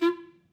DCClar_stac_F3_v3_rr1_sum.wav